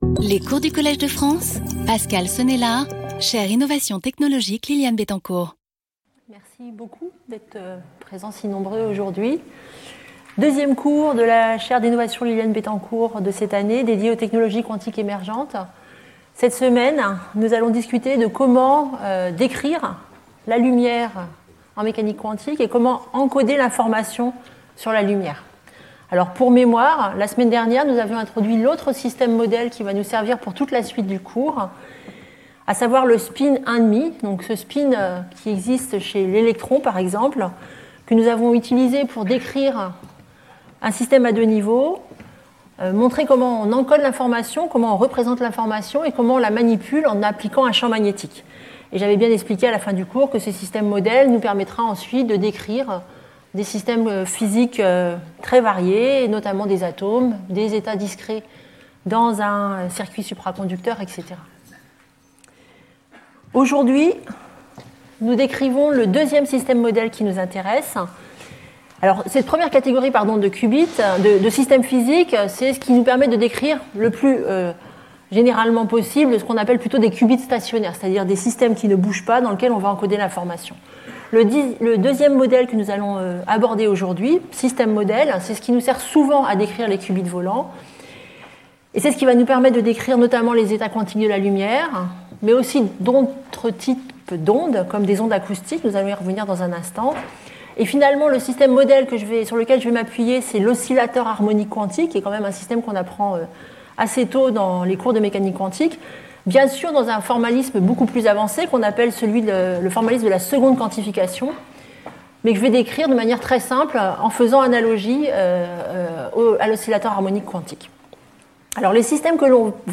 Ce second cours introduira le formalisme permettant de décrire ces systèmes quantiques – basé sur l’oscillateur harmonique quantique. Nous verrons ensuite comment encoder l’information sur la lumière, en exploitant soit des photons uniques, soit les composantes du champ électromagnétique.